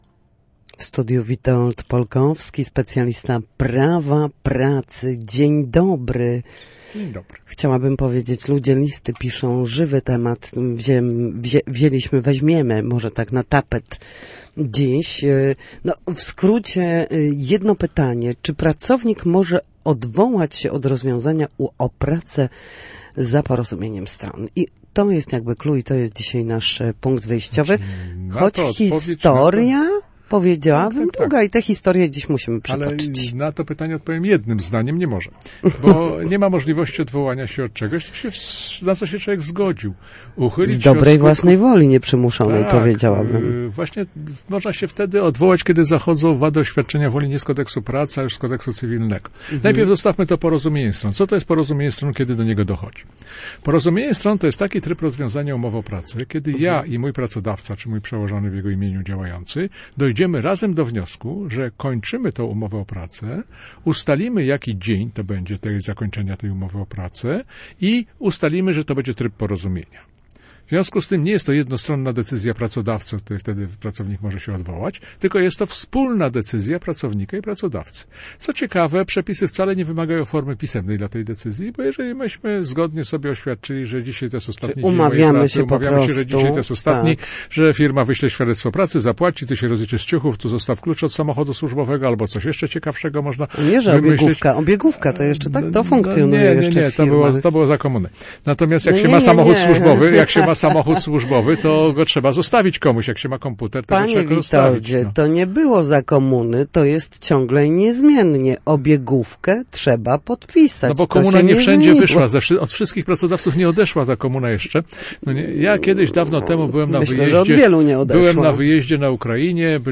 W każdy wtorek po godzinie 13:00 na antenie Studia Słupsk przybliżamy Państwu zagadnienia z zakresu prawa pracy.